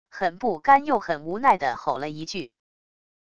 很不甘又很无奈地吼了一句wav音频